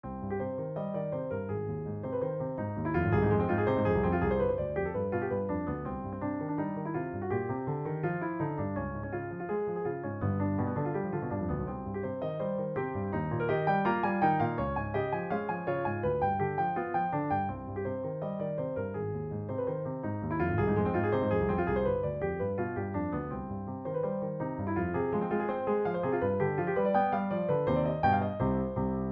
Sonata in C minor (WIP) - Piano Music, Solo Keyboard